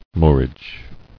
[moor·age]